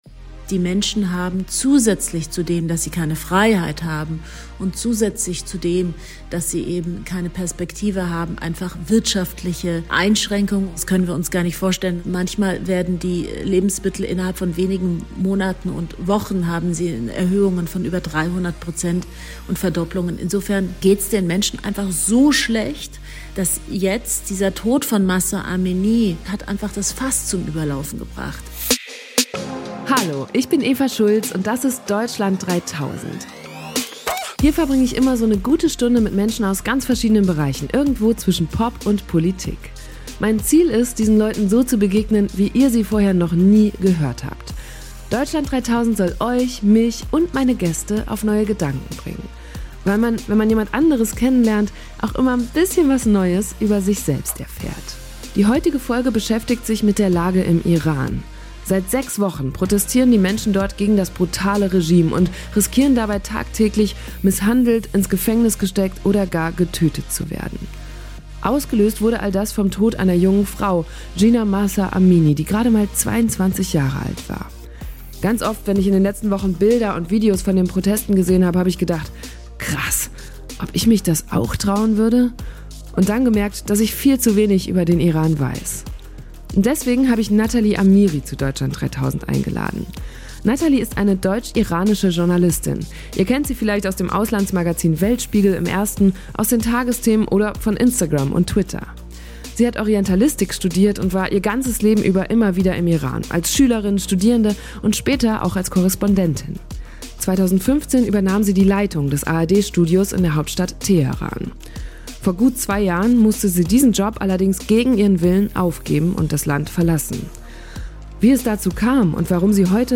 Natalie ist eine deutsch-iranische Journalistin – ihr kennt sie vielleicht aus dem Auslandsmagazin "Weltspiegel" im Ersten, aus den Tagesthemen oder von Instagram und Twitter.
Wie es dazu kam und warum sie heute nicht mehr in den Iran einreisen kann, obwohl sie es gerade jetzt SO gerne würde, hat sie mir im Gespräch erzählt.